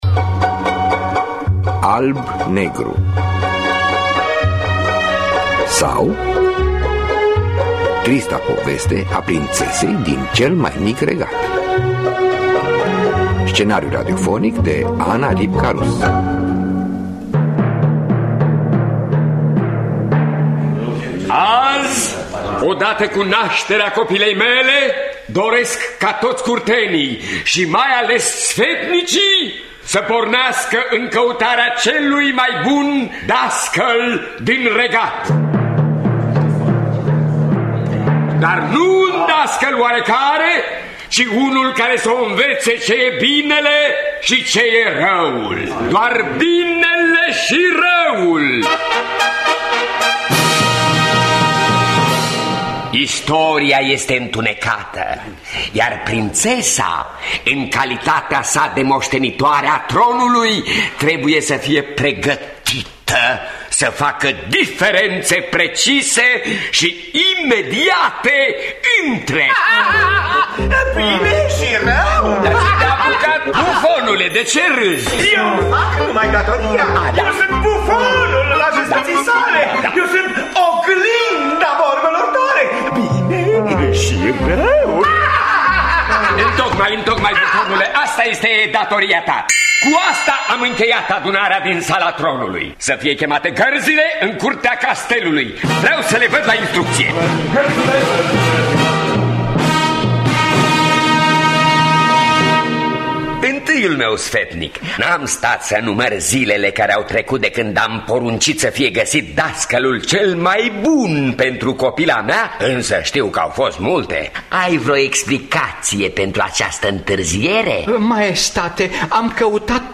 Scenariu radiofonic de Ana Ripka-Rus.